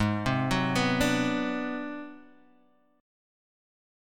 Ab7#9b5 chord